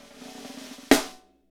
SNR FNK S0DR.wav